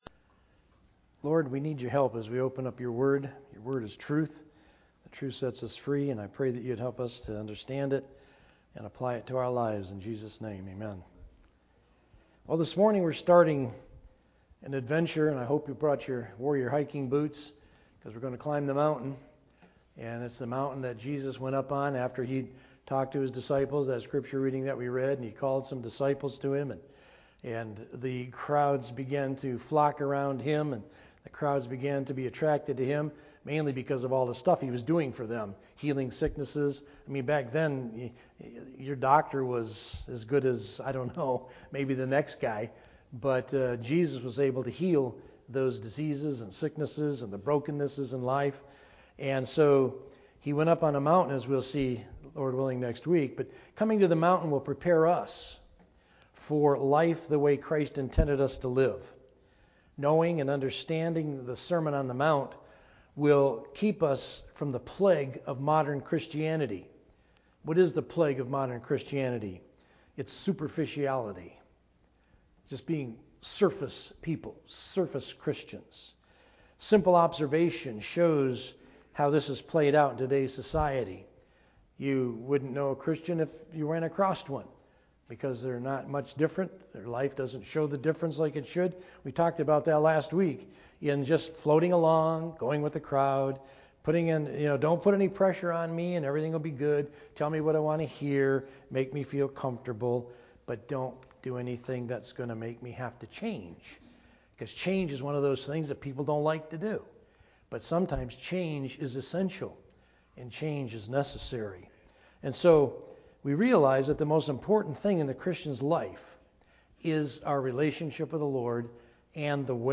Sermon Audio | FCCNB